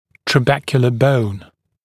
[trə’bekjələ bəun][трэ’бэкйэлэ боун]губчатая кость